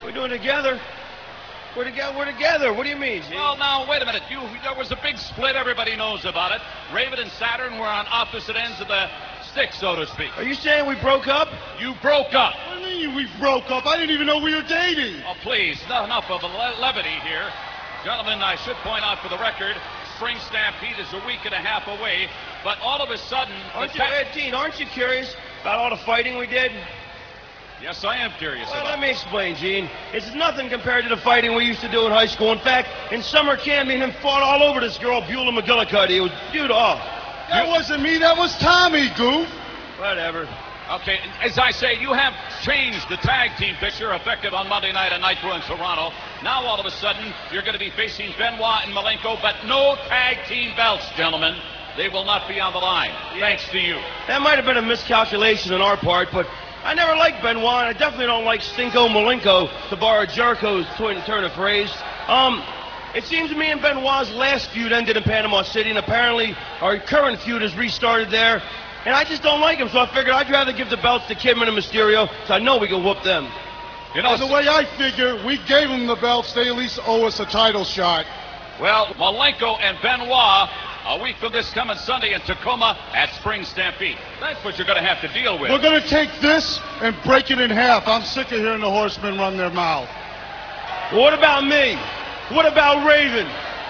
- This interview comes from WCW Thunder - [4.1.99]. Mean Gene talks to Raven and Saturn about their past; how it was Saturn that ended the Flock.